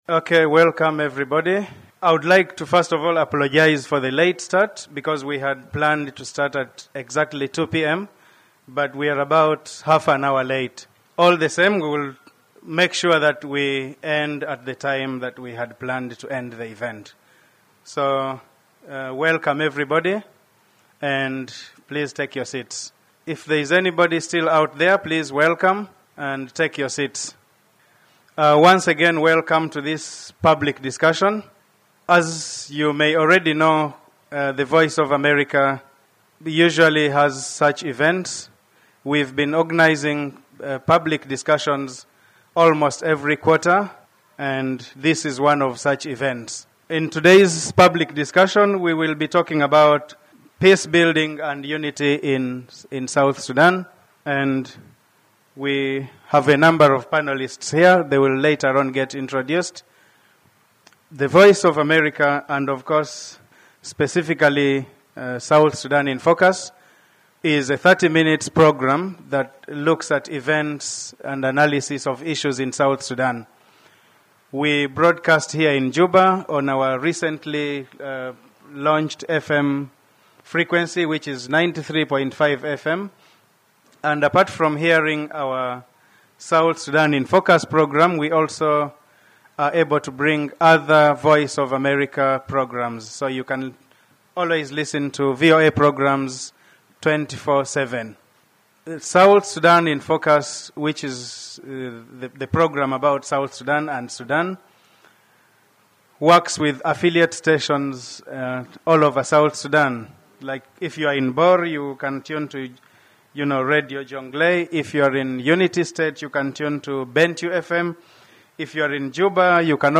More than 100 people attended a lively town hall meeting hosted by Voice of America in Juba.